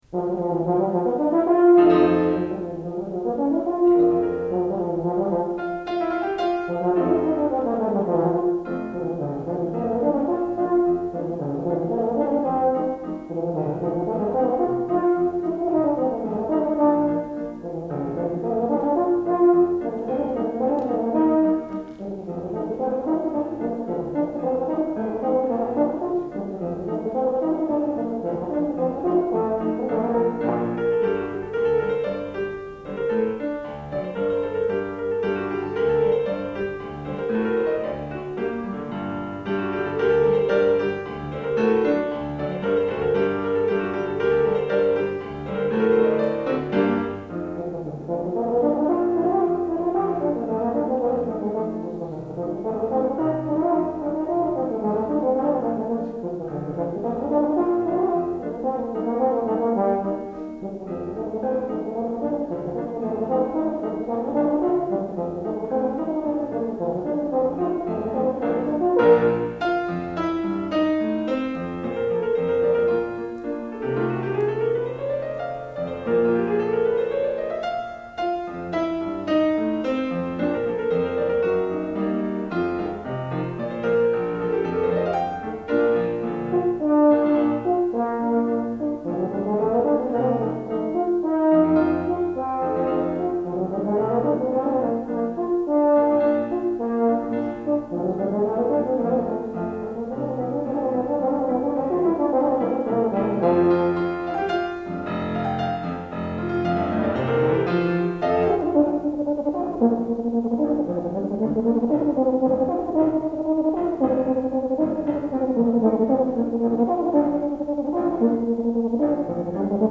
For Euphonium Solo
with Piano.